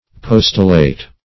Postillate \Pos"til*late\, v. i.